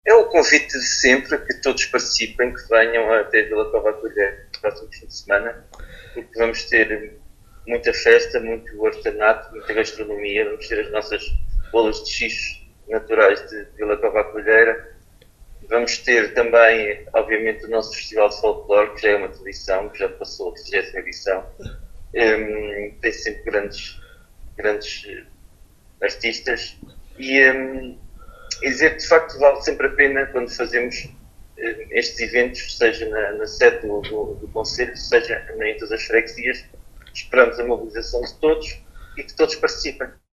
Paulo Marques, Presidente do Município de Vila Nova de Paiva, em declarações à Alive FM, deixa o convite.